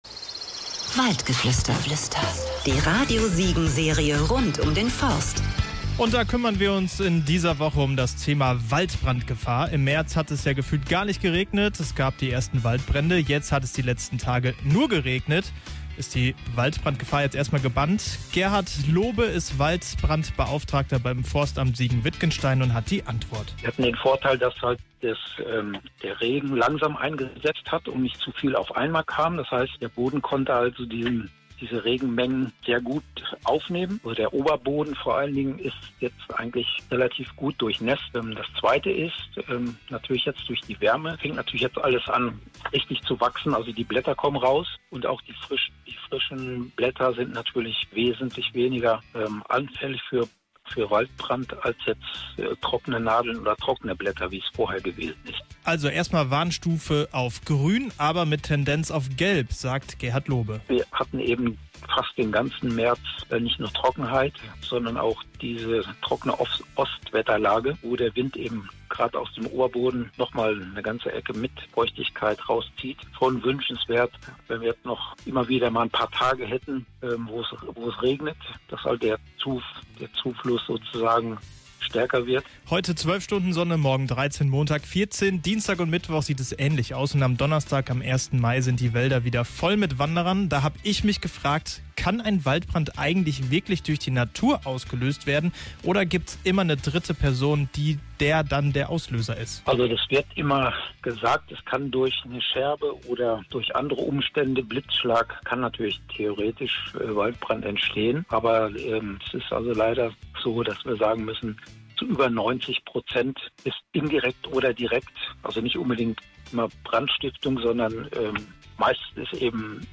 auf einen Spaziergang durch den Wald